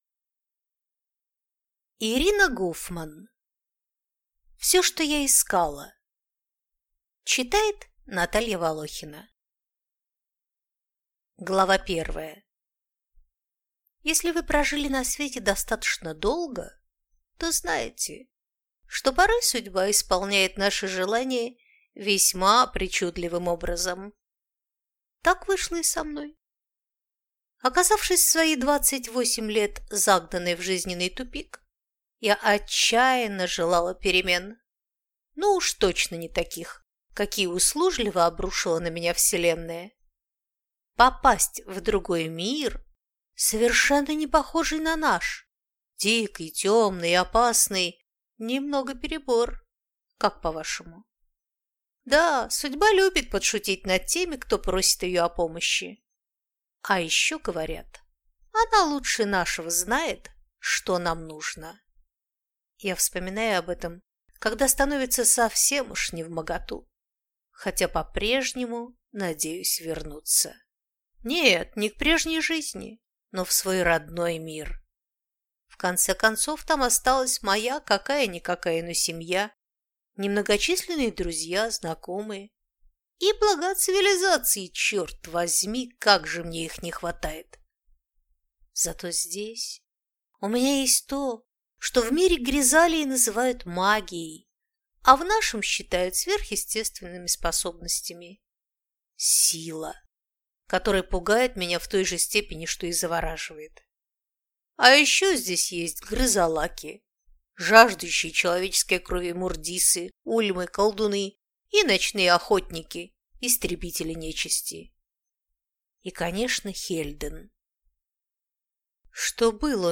Аудиокнига Всё, что я искала | Библиотека аудиокниг